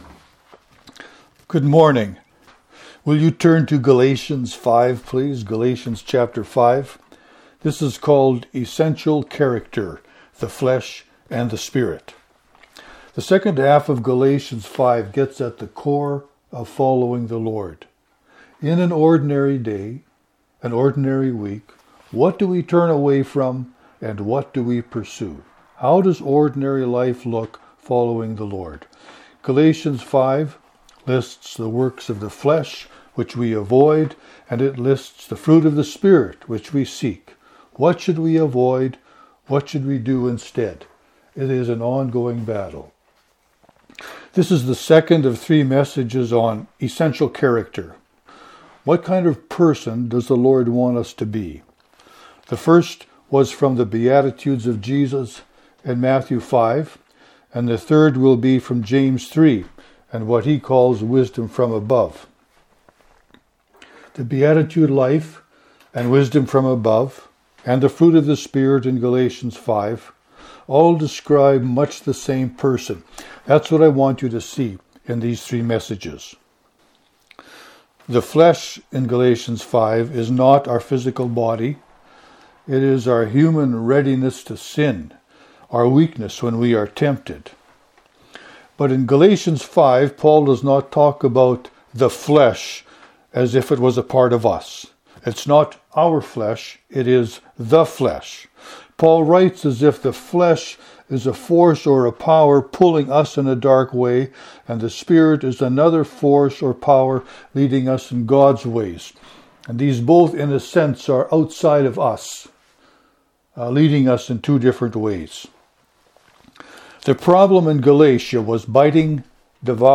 This is the second of three messages on “essential character.”